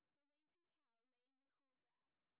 sp17_street_snr20.wav